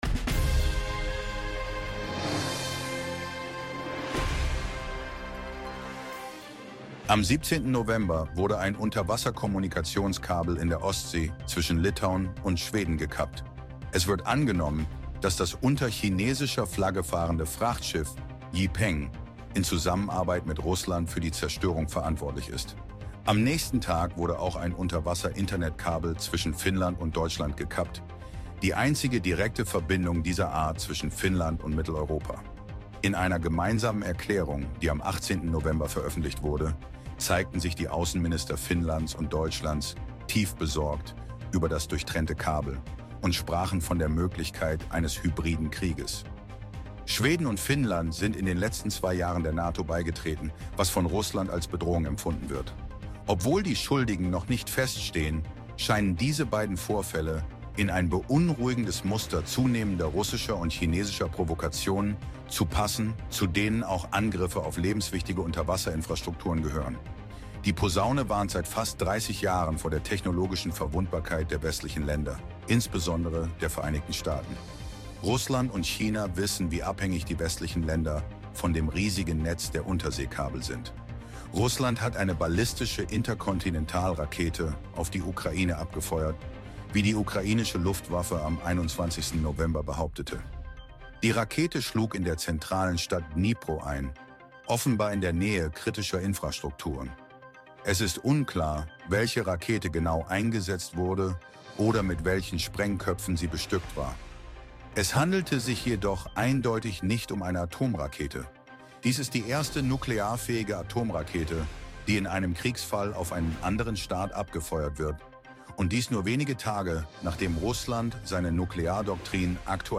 Moderator